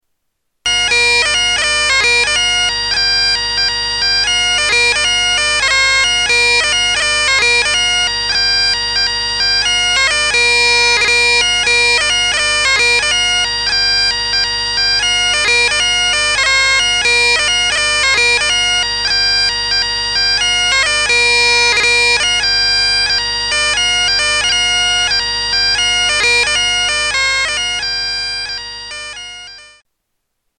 Bagpipes